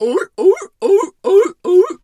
seal_walrus_2_bark_01.wav